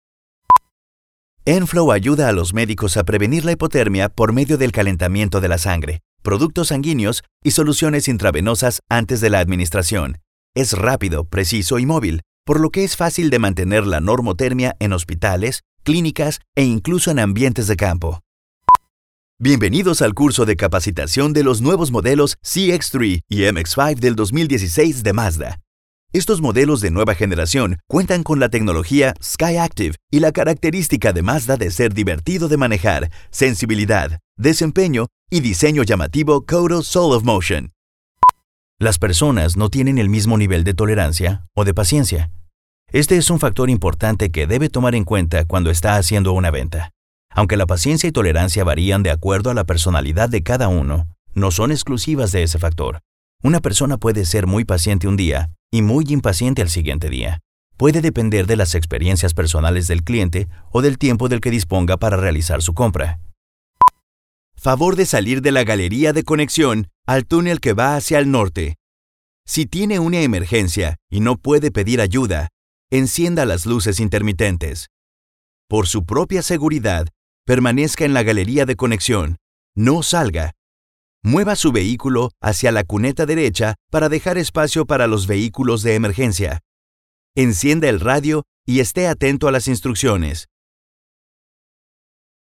Latin American Spanish Voice, VO talent, Spanish Dubbing Actor, Locutor, Announcer, Hispanic Media Producer.
Sprechprobe: eLearning (Muttersprache):